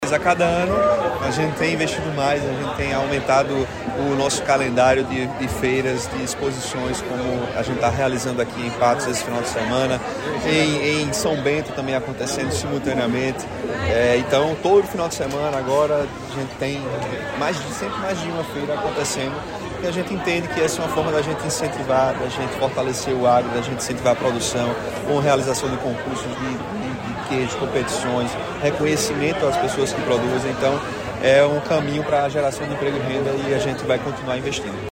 Lucas Ribeiro, destacou que o circuito de exposições, gera emprego e renda na Paraíba, ouça:
SONORA-GOVERNADOR-LUCAS-PATOS-EXPO-26.mp3